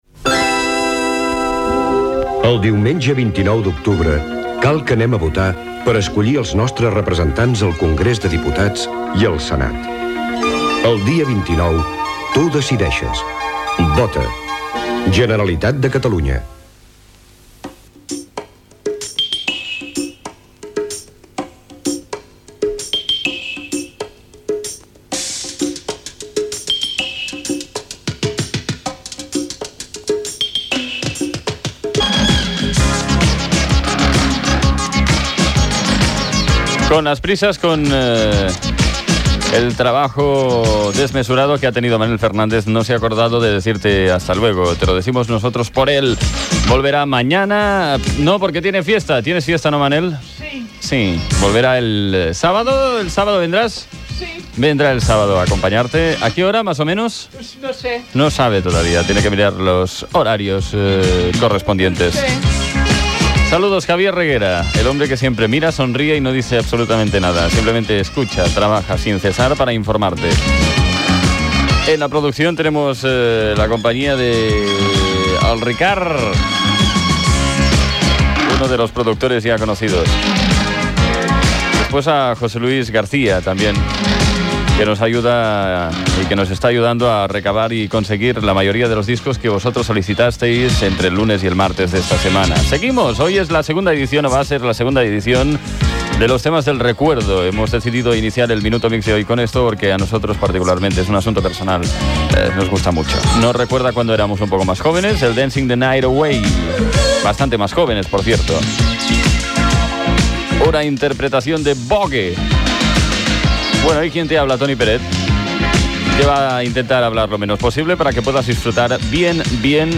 Anunci de les eleccions generals del dia 29 d'octubre, equip del programa, presemtació de la segona edició dels temes per al record, hora, temperatura, publicitat, tema musical, publicitat, indicatiu de la ràdio, hora i temperatura, dos temes musicals, indicatiu, publicitat i tema musical
Musical
FM